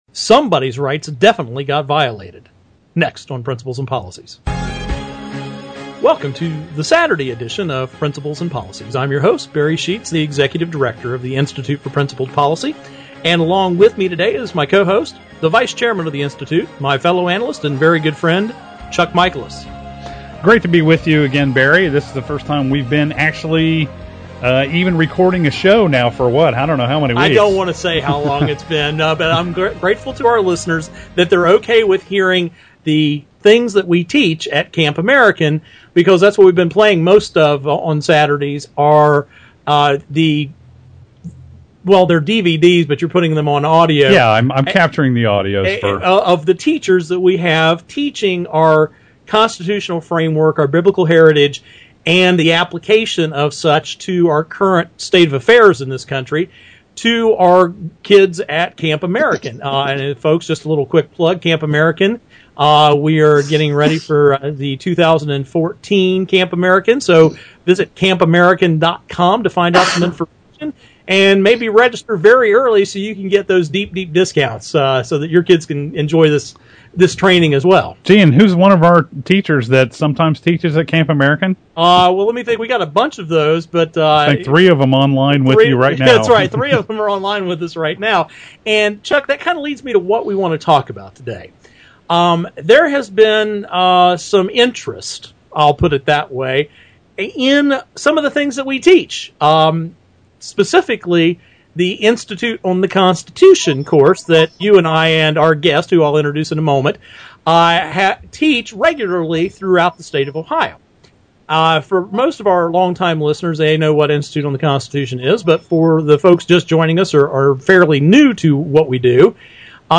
Our Principles and Policies radio show for Saturday July 27, 2013.